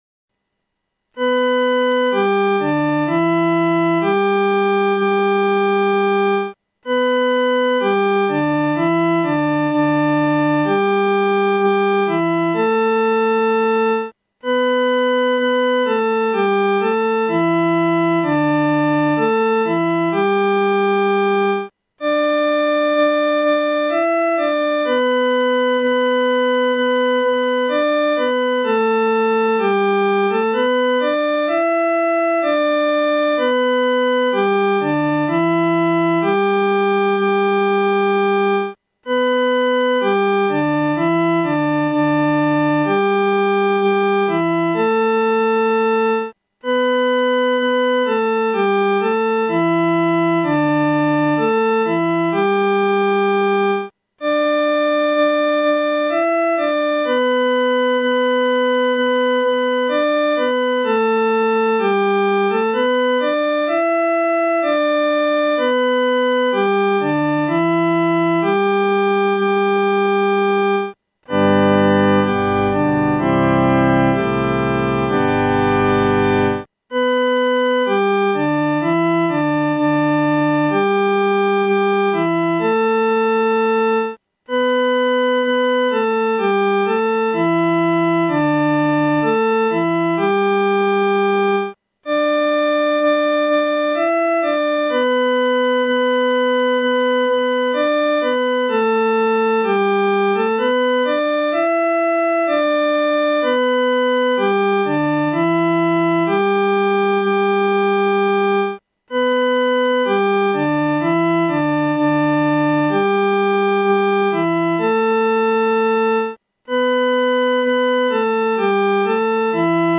伴奏
女高
本首圣诗由网上圣诗班（环球）录制
这首诗歌曲调是按歌词的内容、意境逐渐铺开进行的。前两句平稳幽静，悠然从容。第3句引吭高歌，似与天使一同唱和赞主降生。